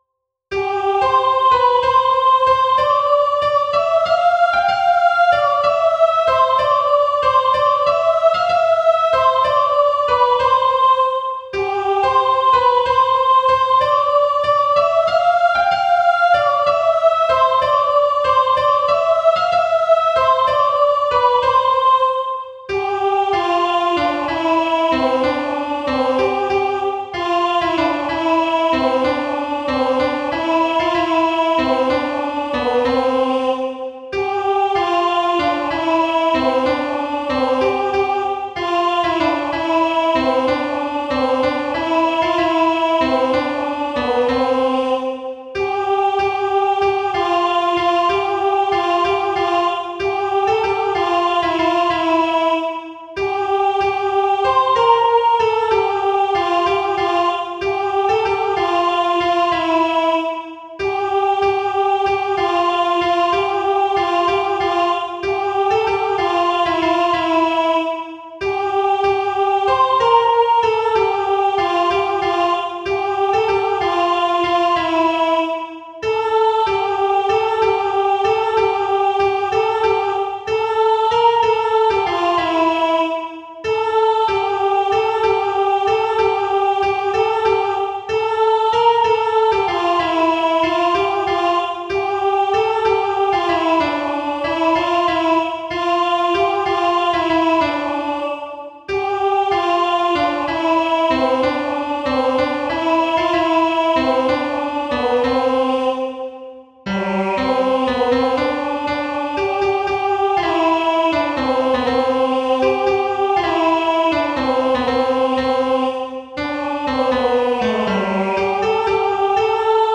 In C Major – در ماهور دو
( تصنيف خوانی و تحليل دستگاهی)
Zolfe-Sar-Kajet-Mahur-WITH-VIOCE-AND-PIANO.wav